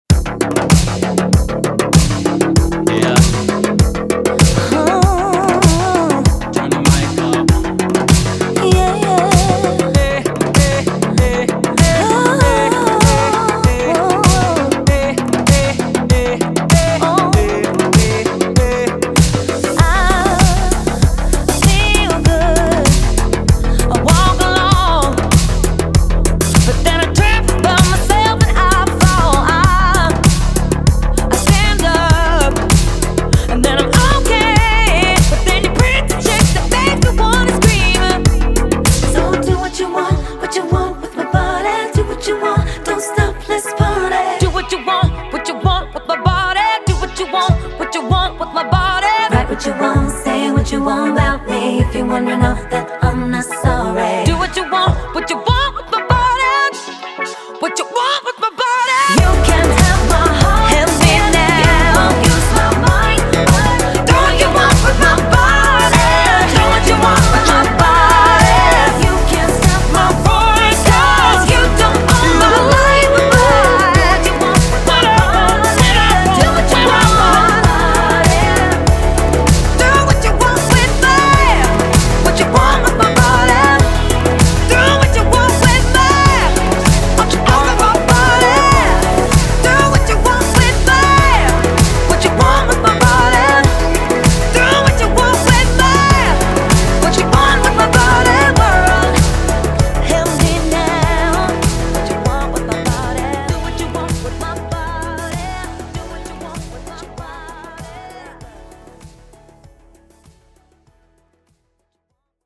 BPM98